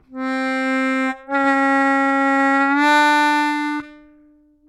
плавно в обратную сторону +1-1′ -1